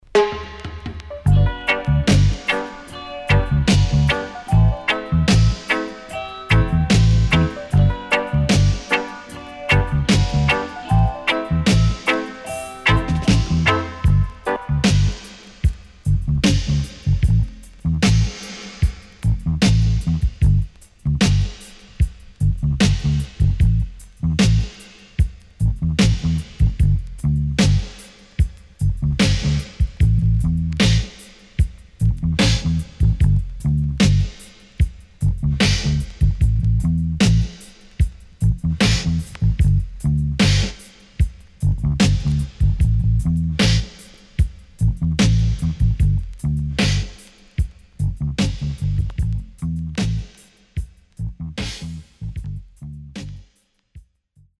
CONDITION SIDE A:VG(OK)〜VG+
Early 80's Great Vocal
SIDE A:少しチリノイズ、プチノイズ入ります。